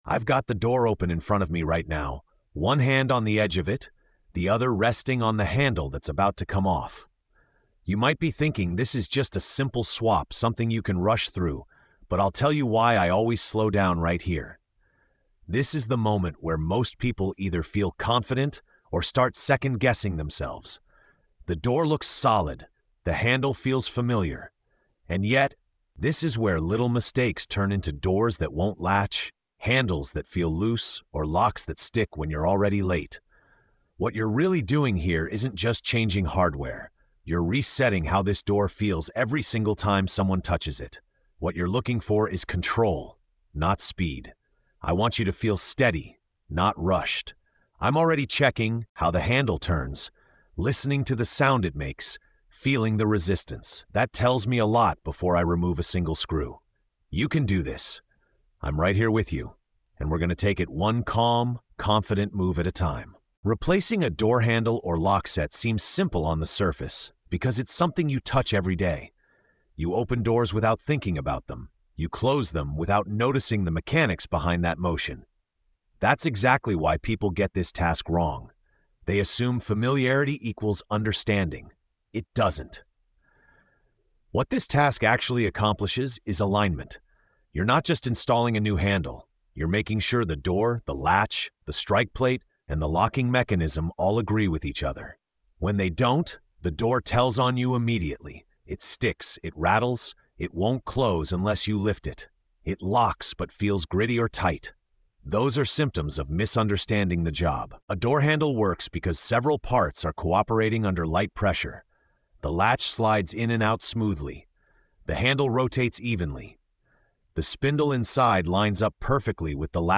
In this episode of TORQUE & TAPE, an experienced tradesman guides the listener through replacing a door handle or lockset—not as a quick hardware swap, but as a lesson in alignment, feel, and professional judgment. The story unfolds at the door itself, focusing on the subtle signals most people ignore: resistance, sound, balance, and touch.